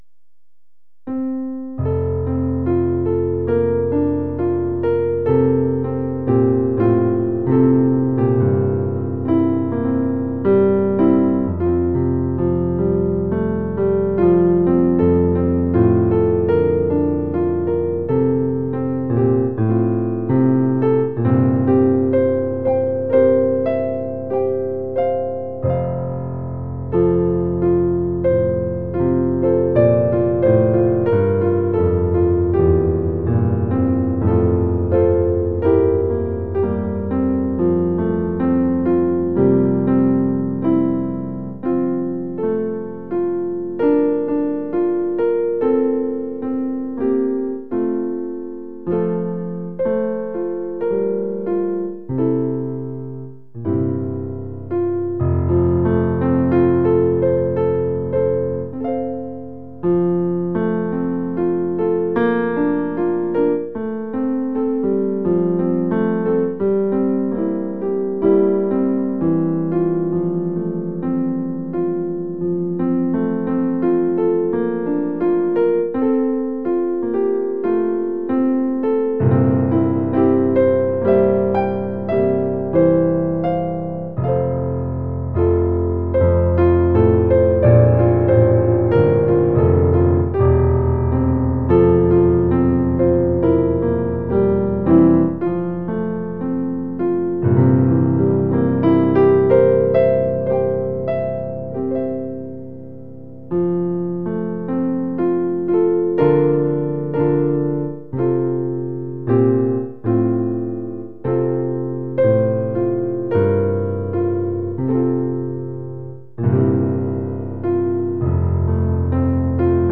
Description:Traditional Hymn
Piano version - MP3 file @ 192Kbps